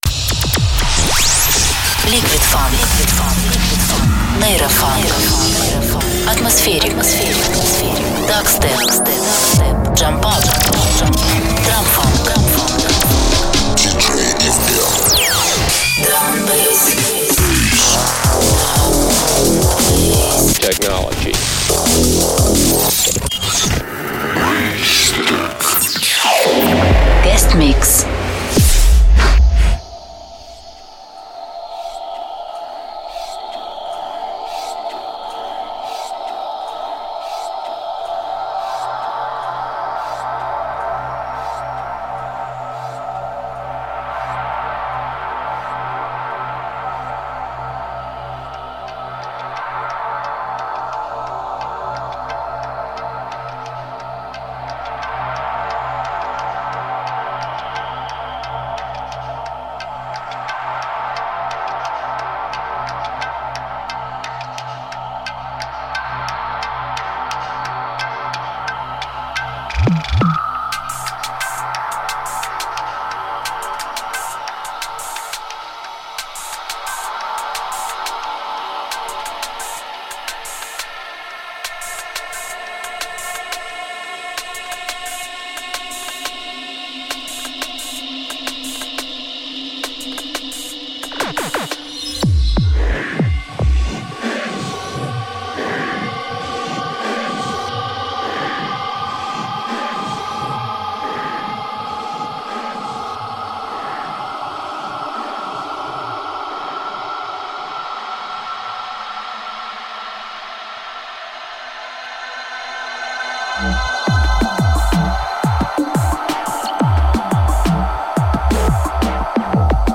hardstep, jump up, liquid funk, neurofunk
версия выпуска без голоса